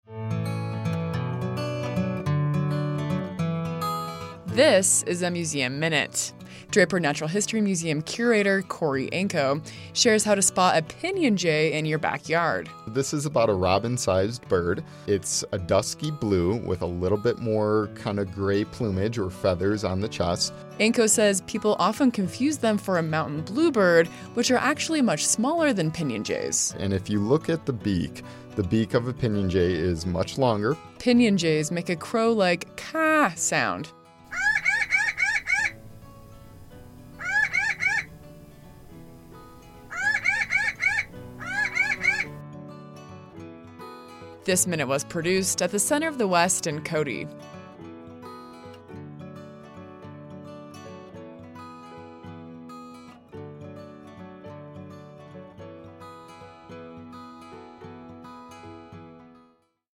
A 1-minute audio snapshot highlighting a museum object from the collection of the Buffalo Bill Center of the West.